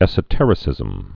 (ĕsə-tĕrĭ-sĭzəm)